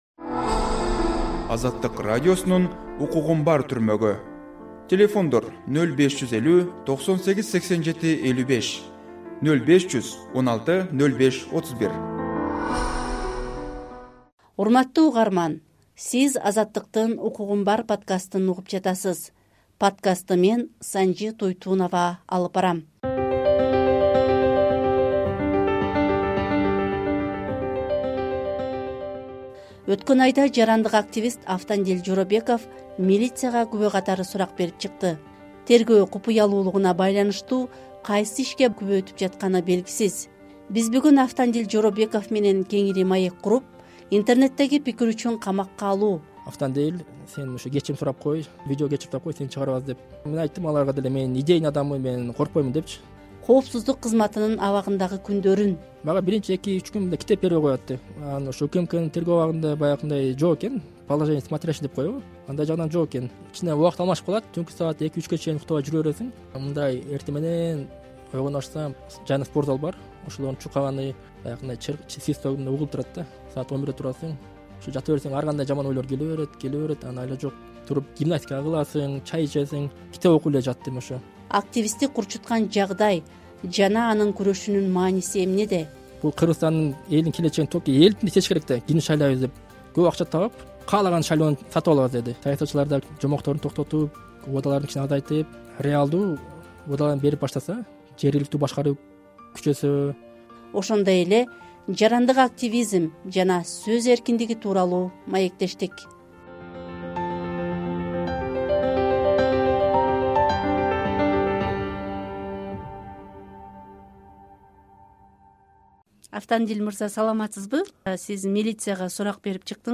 “Укугум бар” подкастында абакта жаткан күндөрү, сөз эркиндиги, жарандык позиция тууралуу маектештик.